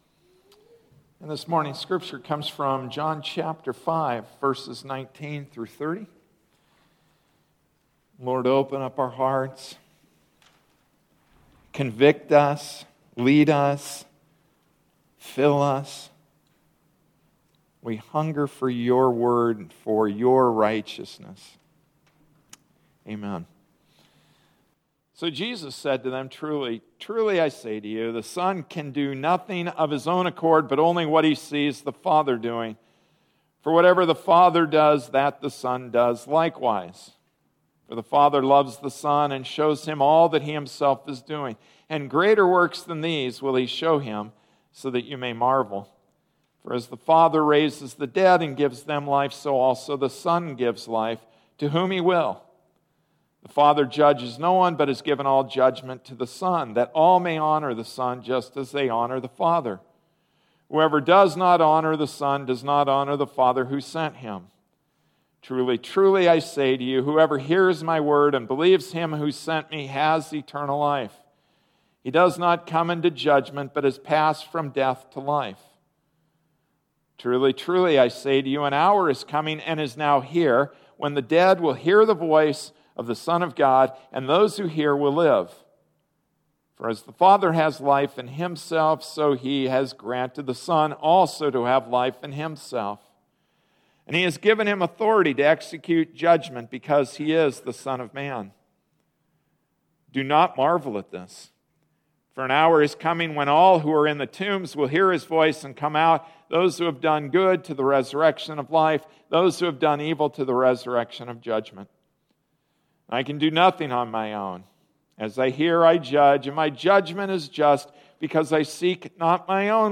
February 23, 2014 Authority Figure Passage: John 5:19-30 Service Type: Sunday Morning Service “Authority Figure,” John 5:19-30 Introduction: We ended last week with a serious charge made against Jesus—He equated himself with God.